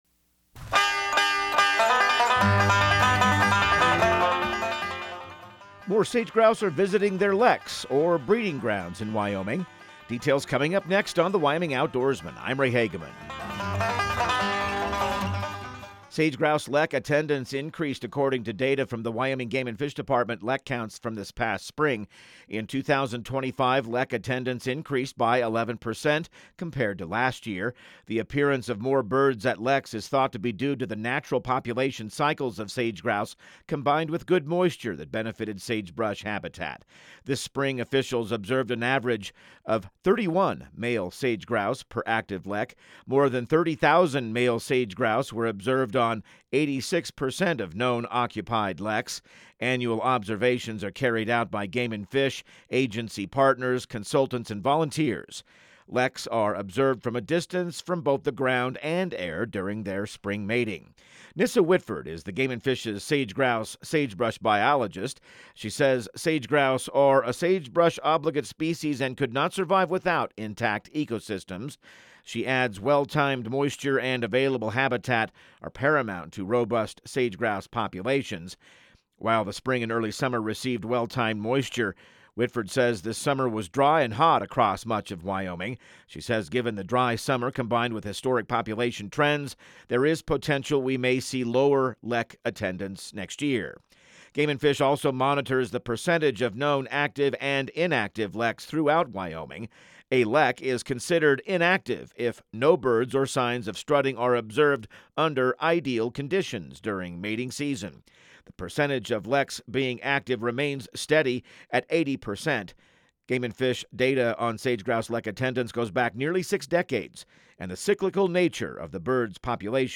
Radio News | Week of September 22